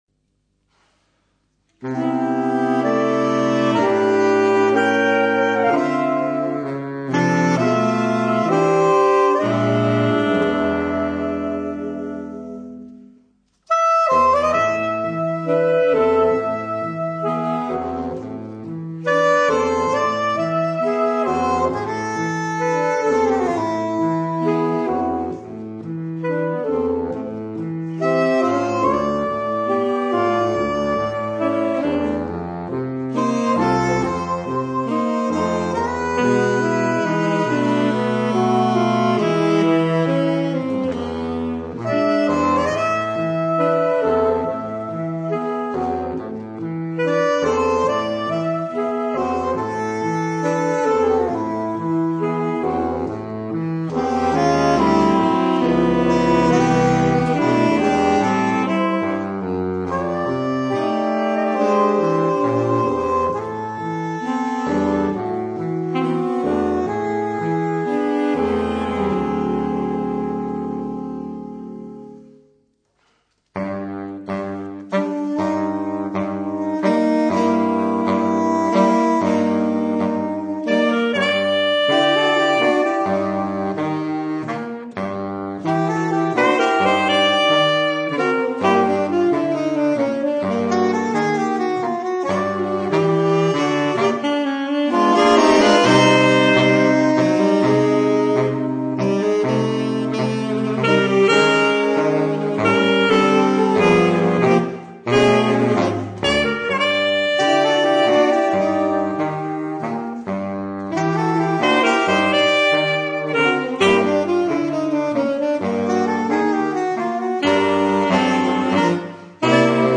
For Woodwinds